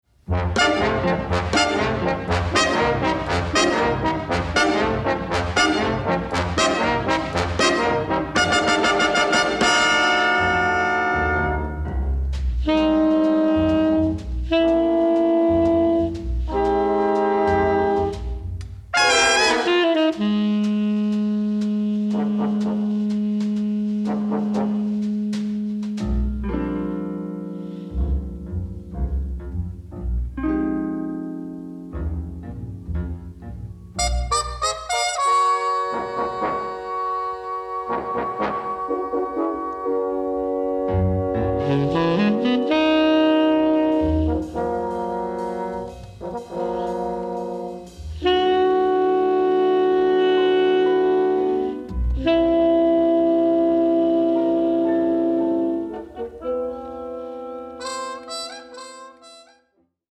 jazz-infused roller-coaster of a score